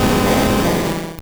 Cri d'Onix dans Pokémon Or et Argent.